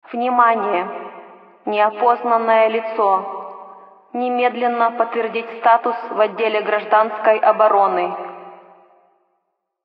Half Life 2 Alarm Russian - Botón de Efecto Sonoro